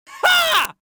Wild Laughs Male 02
Wild Laughs Male 02.wav